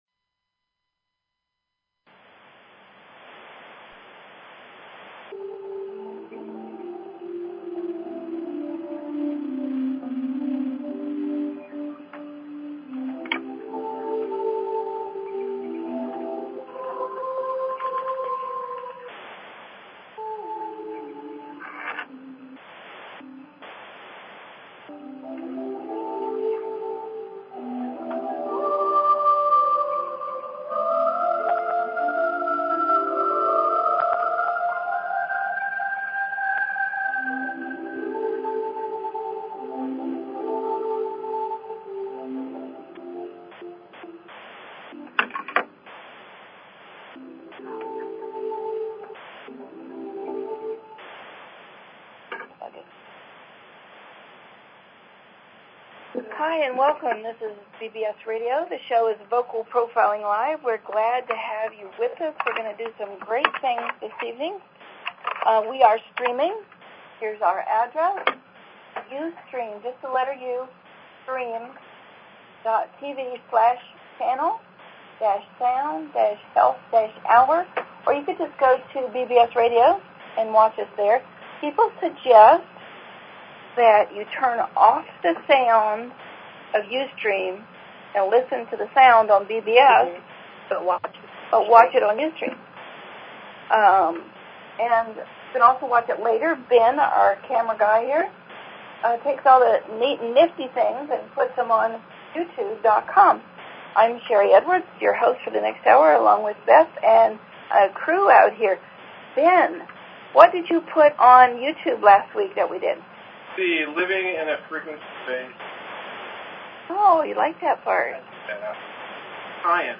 Talk Show Episode, Audio Podcast, Vocal_Profiling_Live and Courtesy of BBS Radio on , show guests , about , categorized as
Thanks to all who called in!